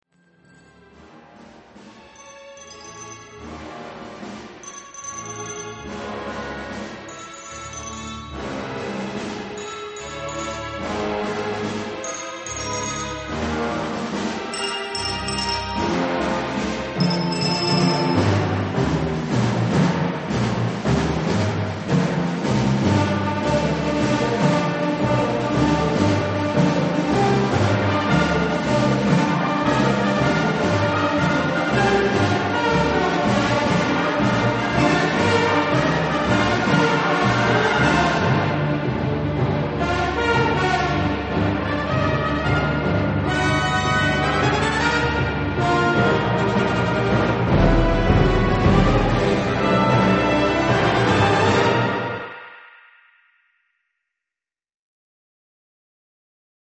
何が言いたいのか良く分からない長い序奏が冒頭から続きます。
ティンパニのソロが一瞬出てきて「お！これは！」と思ったのですが、やられました・・・。
コーダだけむちゃくちゃ吹奏楽！！リズムも良いし。